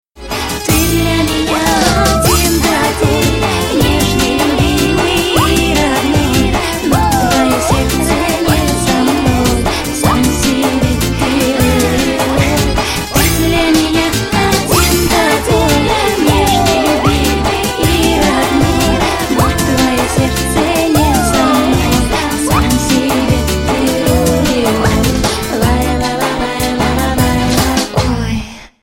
Рингтоны Дискотека 90х
Поп Рингтоны